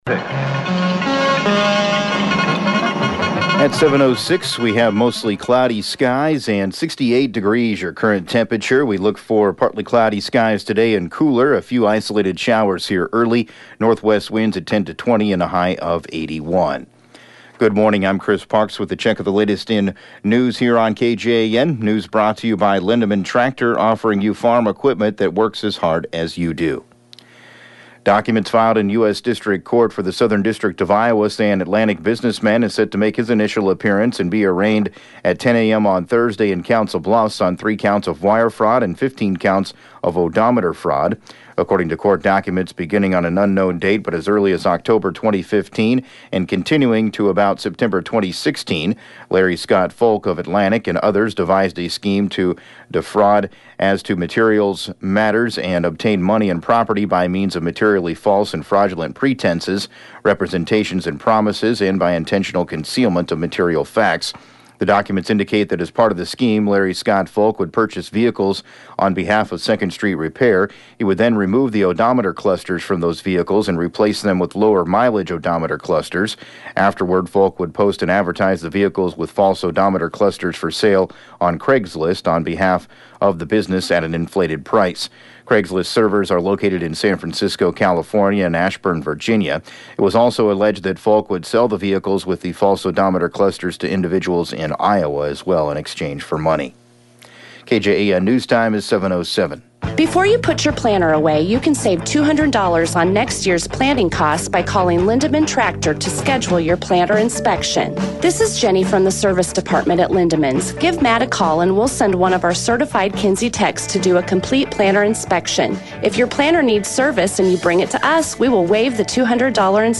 7AM Newscast 07/10/2019